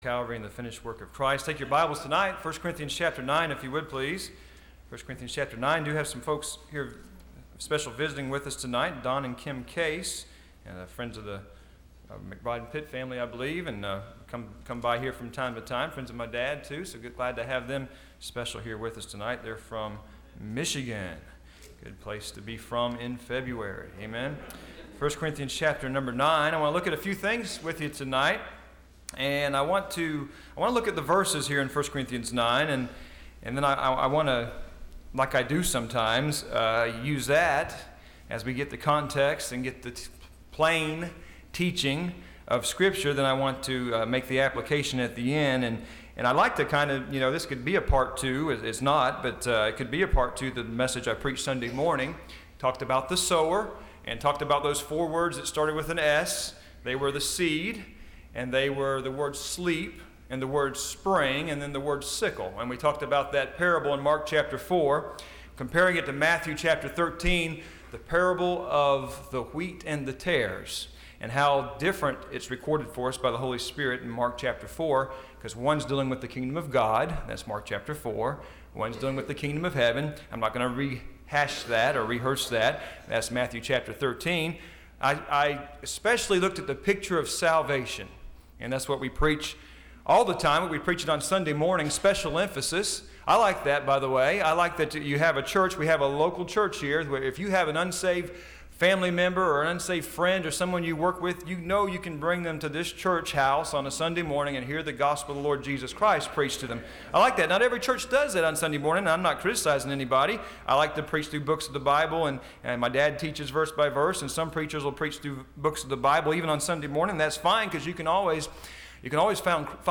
Listen to Message